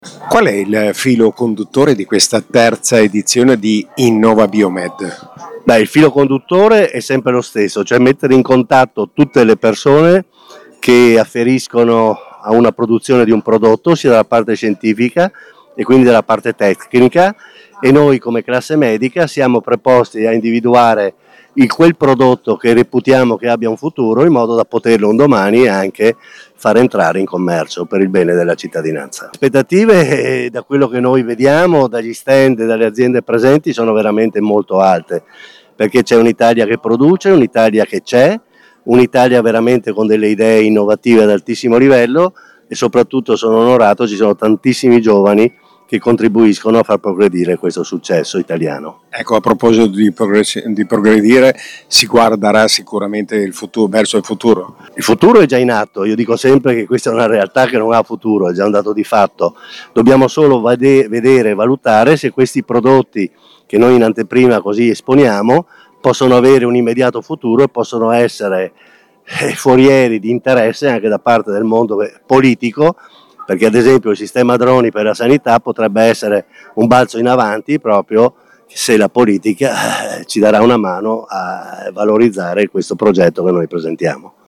Al nostro microfono anche: